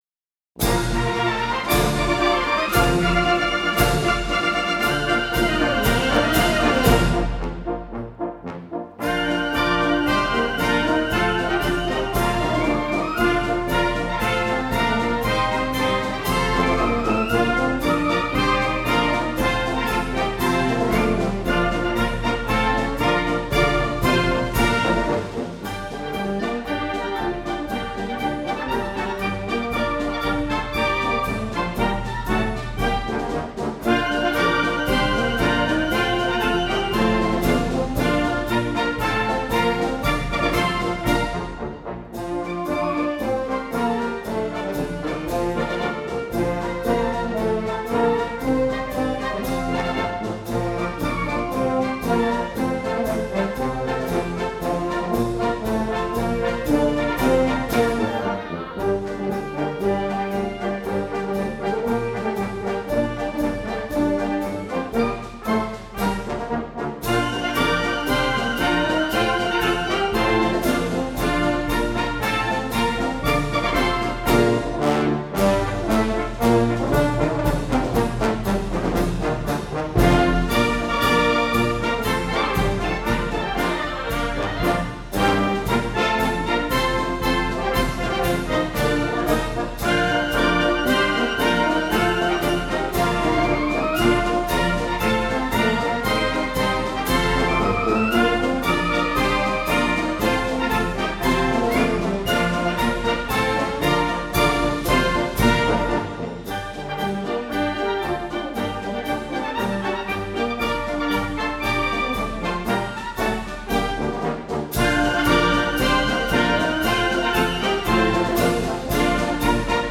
进行曲是一种用节奏写成的乐曲，富有步伐节奏。
雄劲刚键的旋律和坚定有力的节奏是进行曲的基本特点。
军乐一般重声势，旋律明快、高昂，以此起鼓舞和振奋之作用。